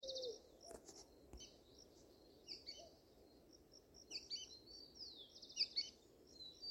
Sooty-fronted Spinetail (Synallaxis frontalis)
Detailed location: En las afueras del pueblo
Condition: Wild
Certainty: Recorded vocal